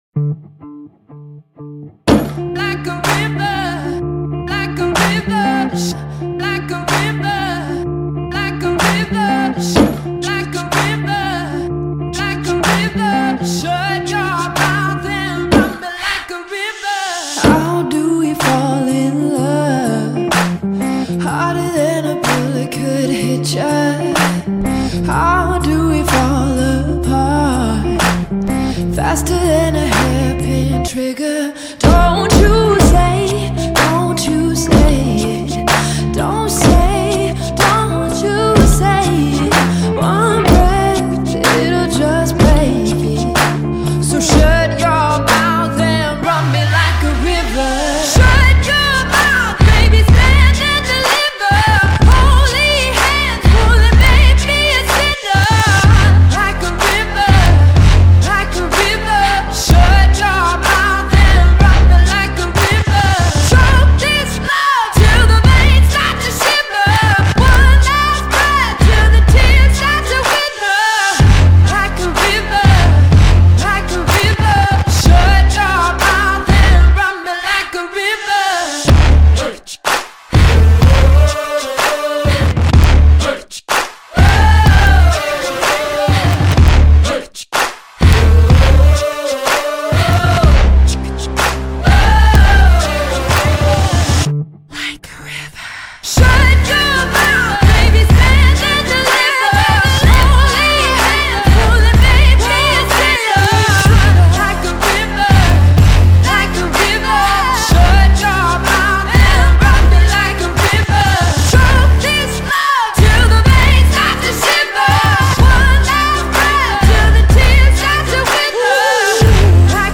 BPM125
A current alt rock hit, with a swing.